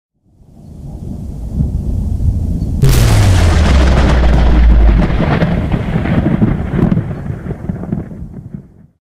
Loud Thunder Strike Sound Effect Free Download
Loud Thunder Strike